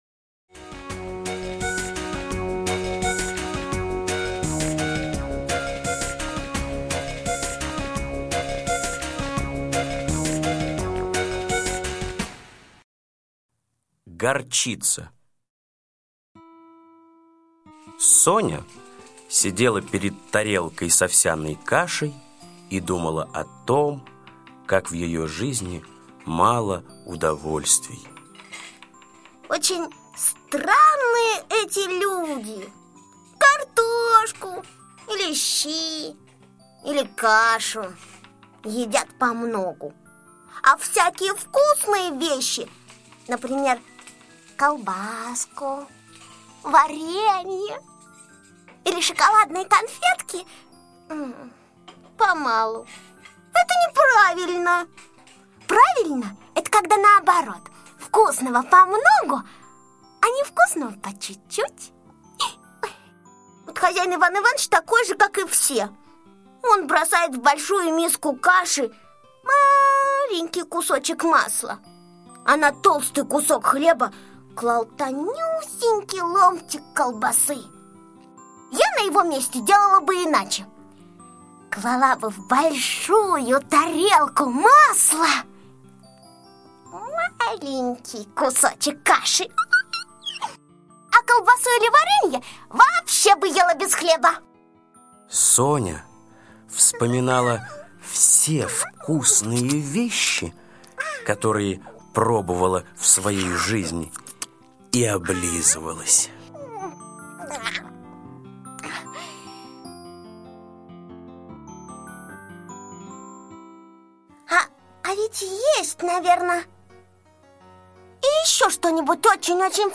Горчица - аудиосказка Усачева А.А. Собачка Соня заметила, что все вкусные продукты все едят помаленьку и решила попробовать горчицу.